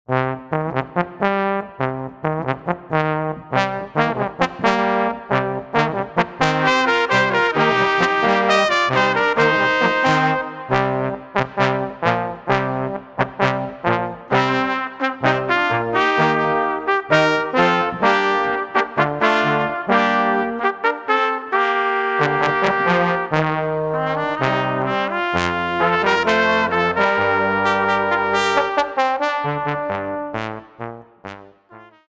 Wonderful settings of Luther songs in the typical swinging